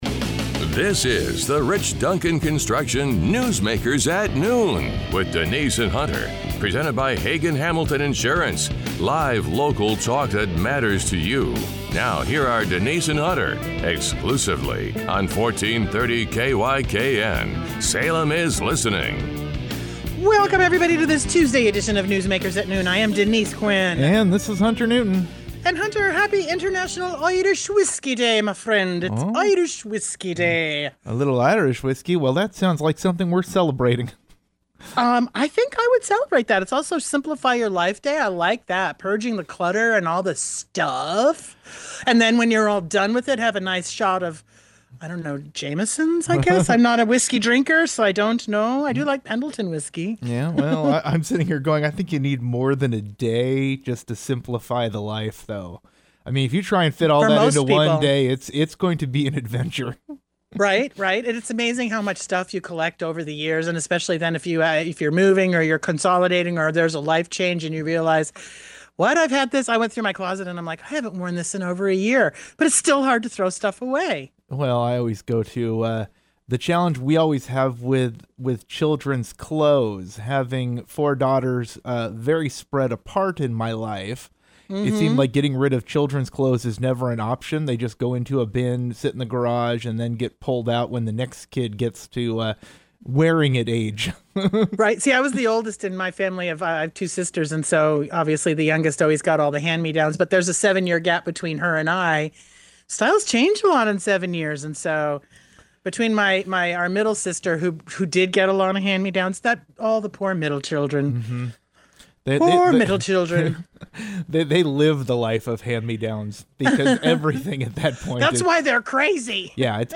The conversation then shifts to Oregon politics, where debate is heating up over the decision to move a proposed $4 billion tax measure from the November general election to the lower-turnout May primary. The hosts discuss voter participation numbers, legislative reaction, and what it could mean for accountability and public trust.